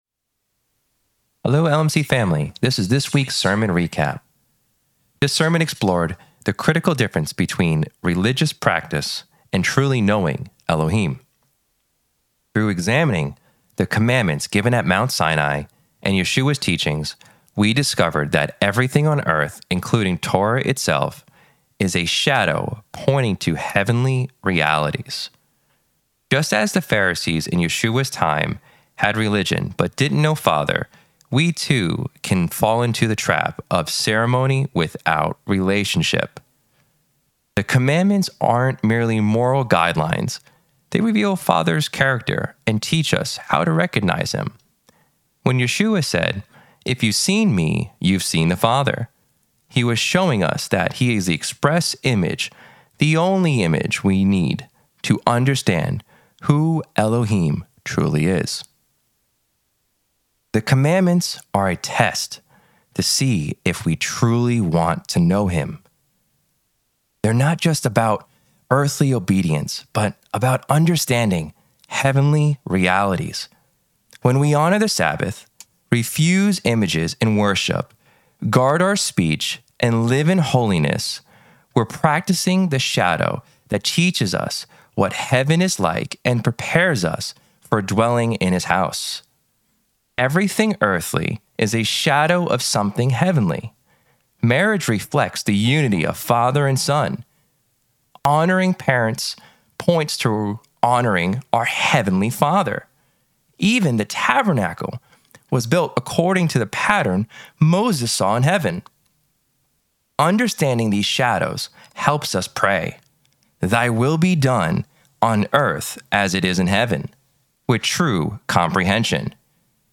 Sermon Audio Recap